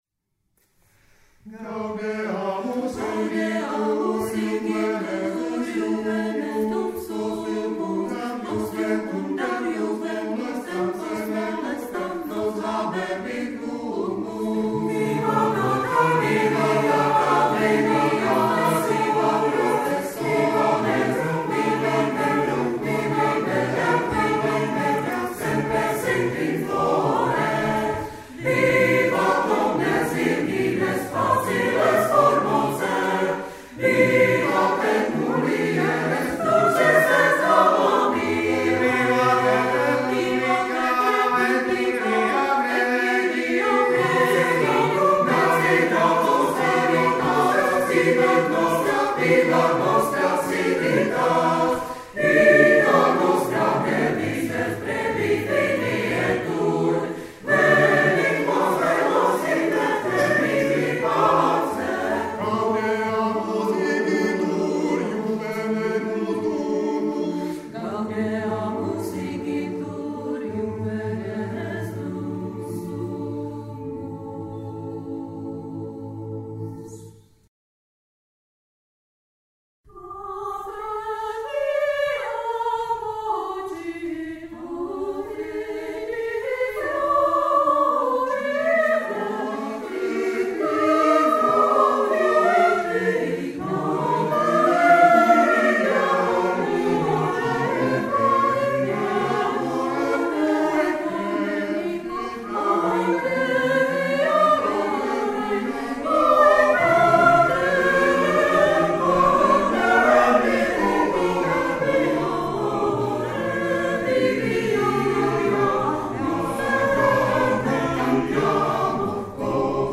Zvuková ukázka z vystoupení v kostele sv. Jan Evangelisty v Towbridge